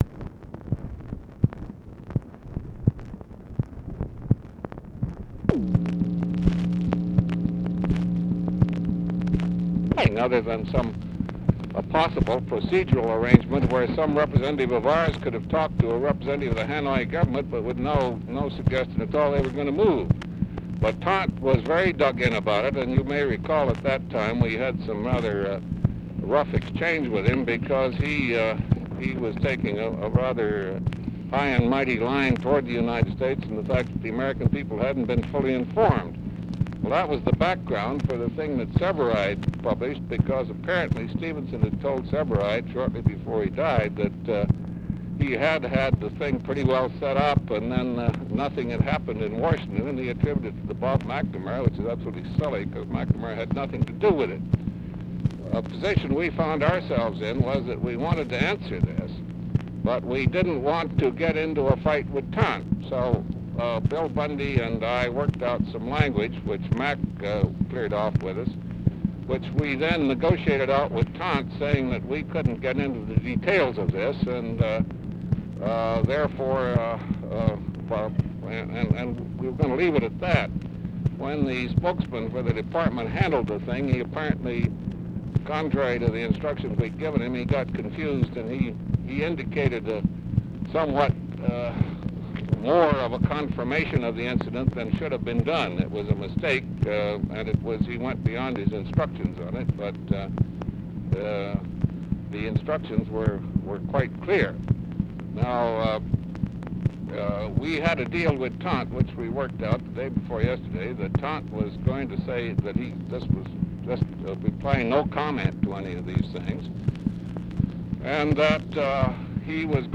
Conversation with GEORGE BALL, November 16, 1965
Secret White House Tapes